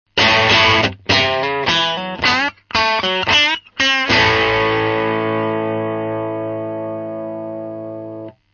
(NoEQ,NoEffectで掲載しています）
No.4 MP3 LiveLine製のパッチを二本使用。
思ったより太い音です。もうちょっとローがあるとバランス良いのかもしれません。